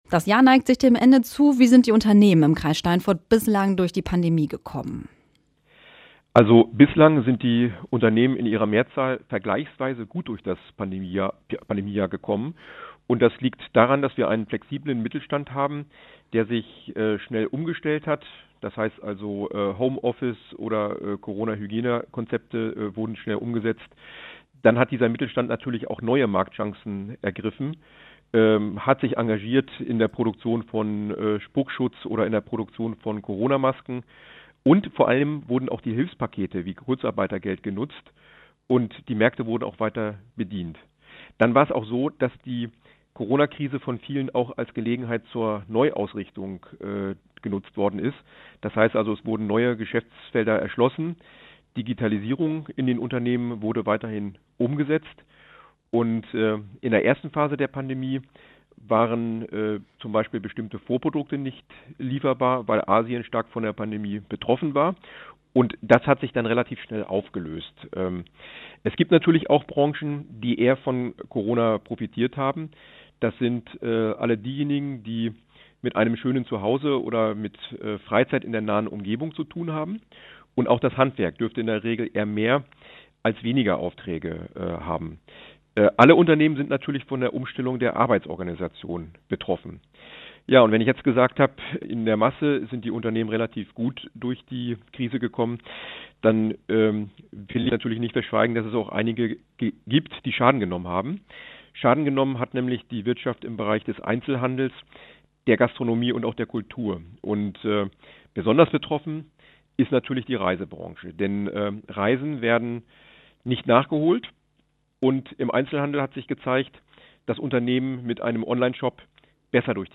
Wir sprechen mit ihm über die steigenden Coronazahlen, den Impfstart und die Stimmung in der Wirtschaft.